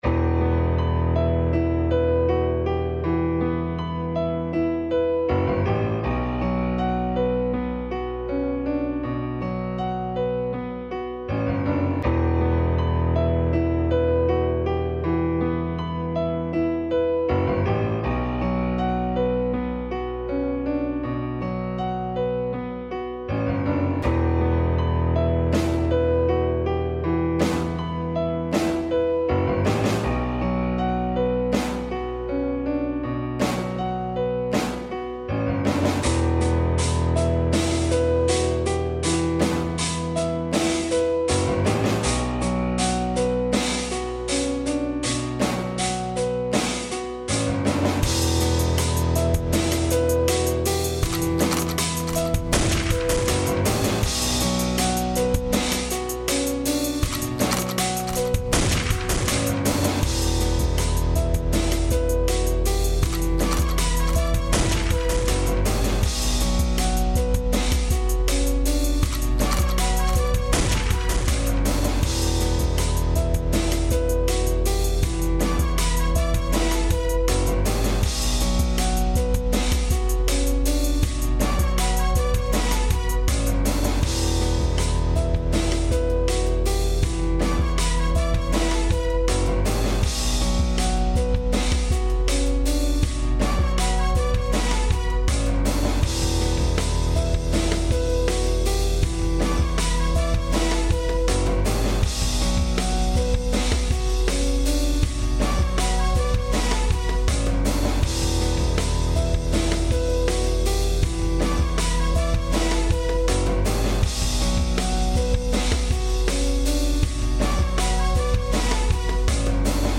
sountrack type